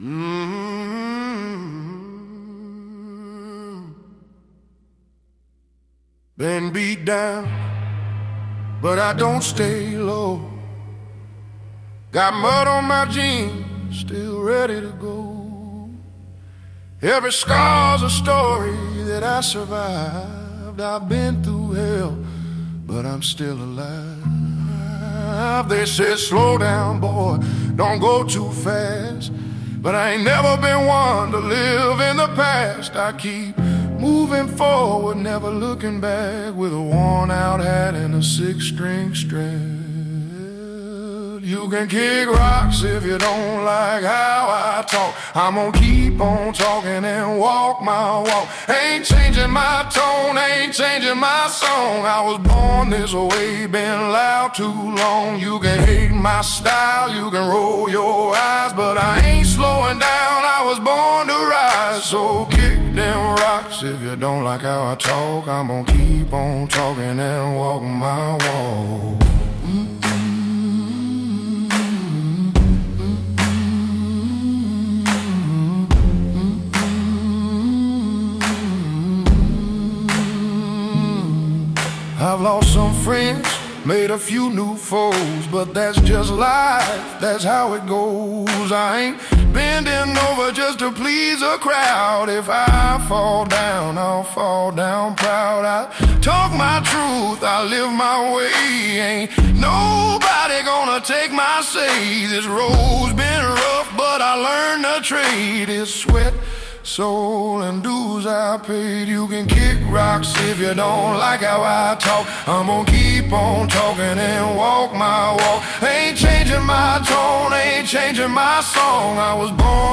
AI-Generated Music
a song 100% generated by AI